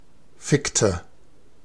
Ääntäminen
Ääntäminen Tuntematon aksentti: IPA: /ˈfɪktə/ Haettu sana löytyi näillä lähdekielillä: saksa Käännöksiä ei löytynyt valitulle kohdekielelle. Fickte on sanan ficken imperfekti.